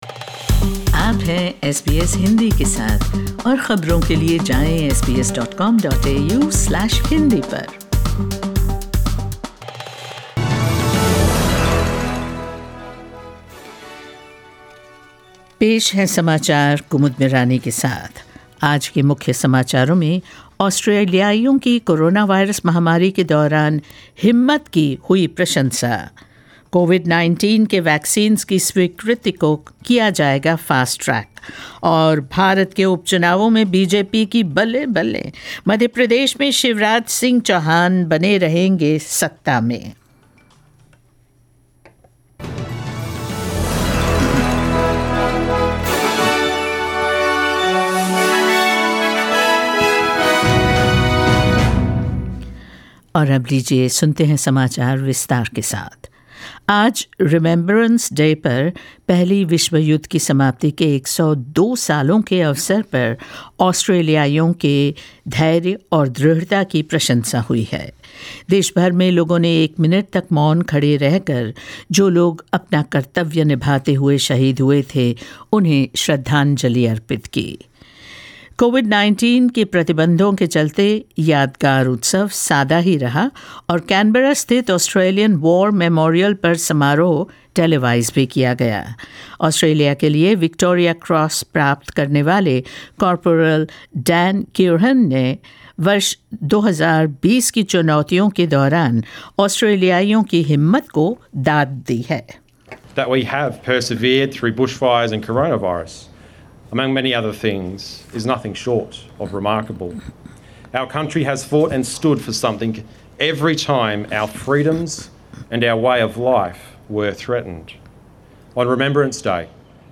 News in Hindi on 11 November 2020